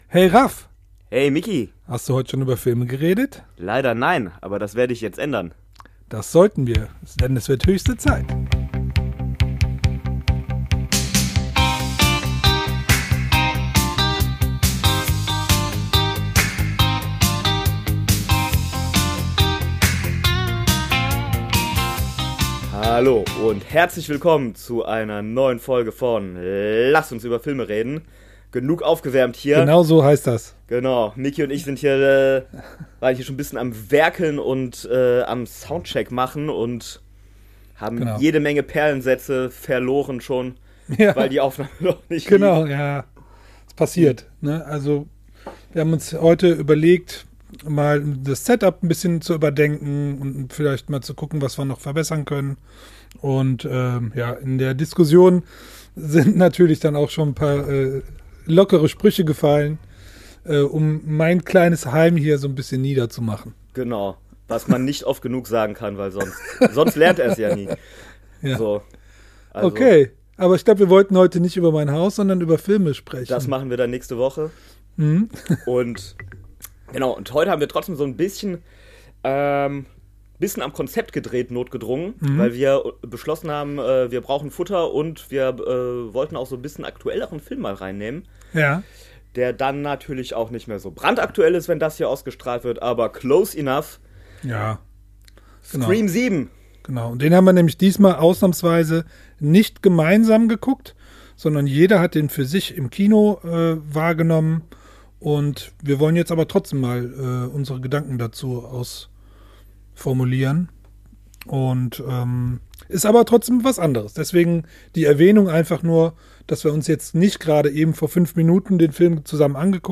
Ein lockerer Plausch über das Scream-Universum, seine Höhen & Tiefen und den Einfluss auf den Werdegang des Horror-Genres.